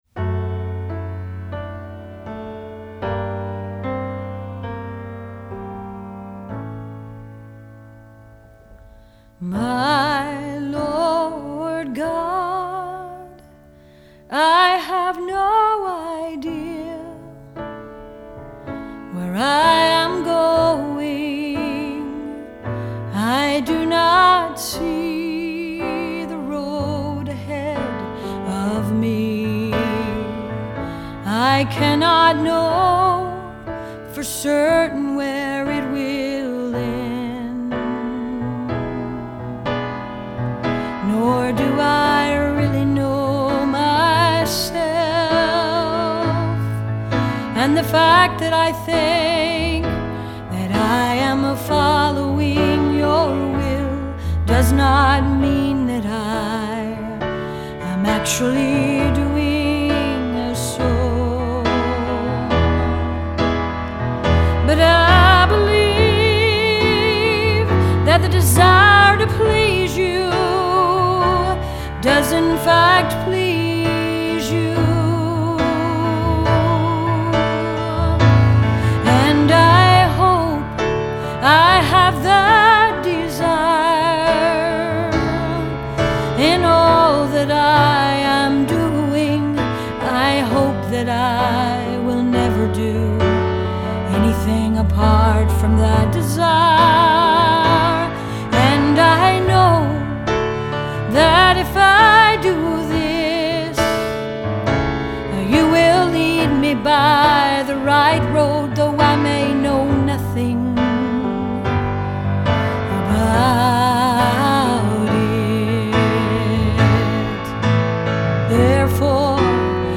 her beautiful voice singing